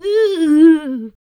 46 RSS-VOX.wav